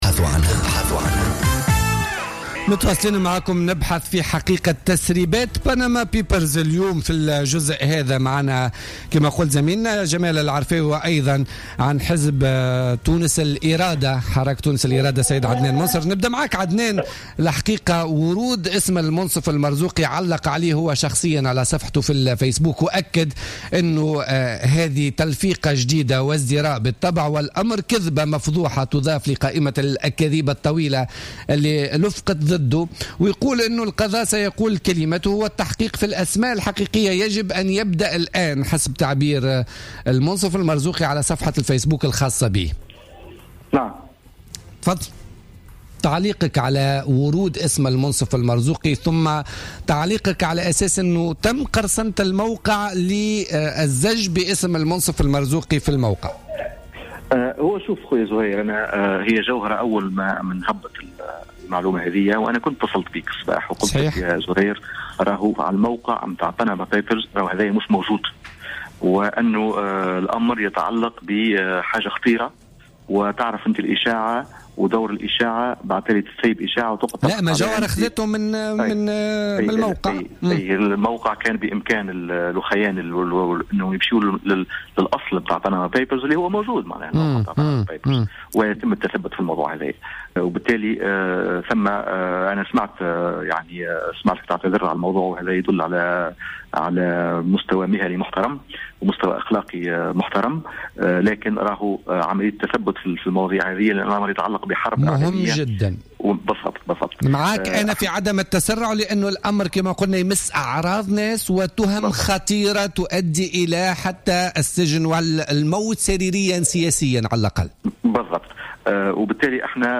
أكد عدنان منصر عن حزب حراك تونس الإرادة في مداخلة له في بوليتيكا اليوم الثلاثاء 5 أفريل 2016 أن الزج بإسم منصف المرزوقي في قضية "وثائق بنما" دون التثبت يعتبر أمرا خطيرا.